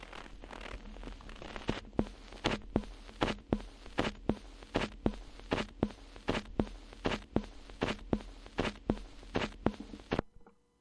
描述：耗尽78转的旧记录
Tag: 78 老唱片 噼啪作响 弹出